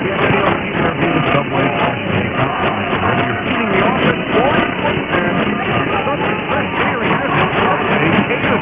[IRCA] WYTH test heard in DC
No voice heard at any time.
Thanks goodness for CW IDs and sweep tones!
Perseus/Wellbrook 4-element delta loop array
wyth_cw_id.wav